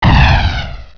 spellhit.wav